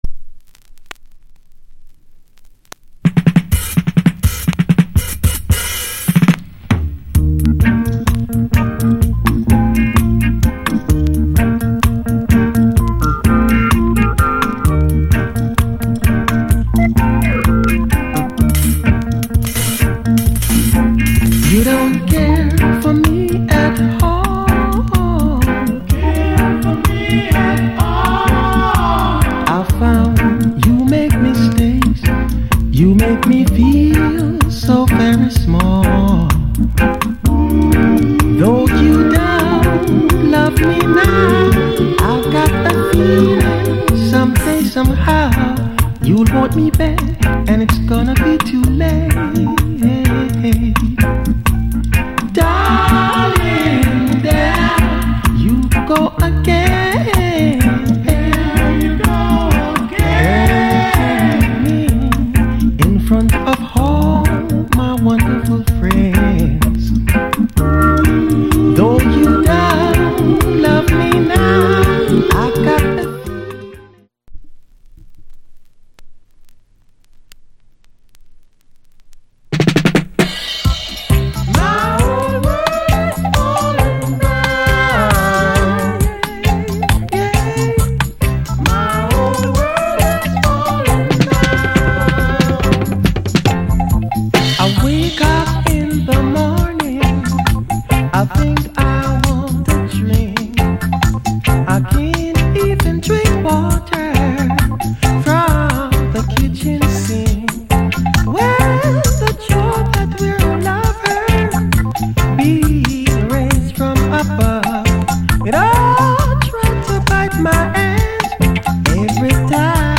Genre Reggae70sLate / Male Vocal Group Vocal